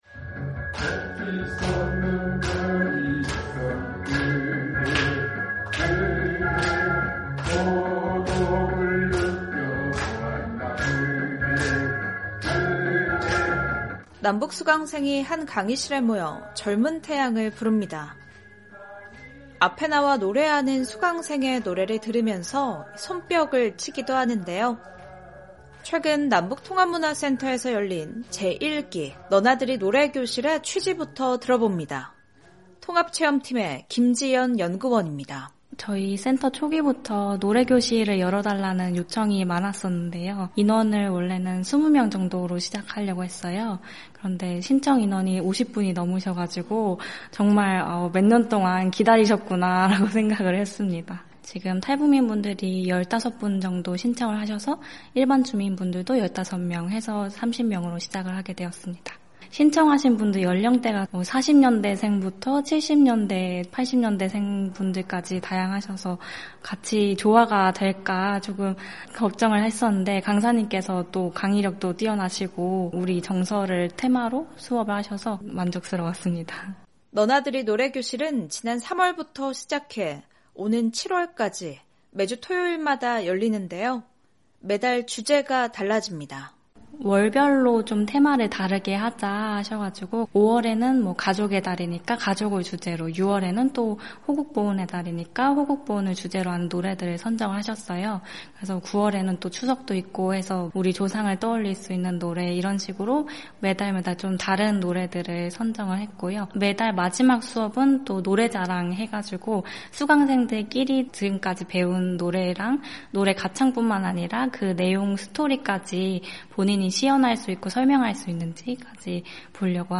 남북 주민이 어울려 대중가요 속에서 한민족의 정서를 느끼고 문화 공감과 소통의 장을 만드는 노래교실이 열리고 있습니다. 대중가요를 통해 노래 가창뿐만 아니라 매주 바뀌는 주제곡의 역사와 시대상을 배우기도 하는데요. 탈북민들의 다양한 삶의 이야기를 전해드리는 ‘탈북민의 세상 보기’, 오늘은 ‘제1기 너나들이 노래교실’ 현장으로 안내해 드립니다.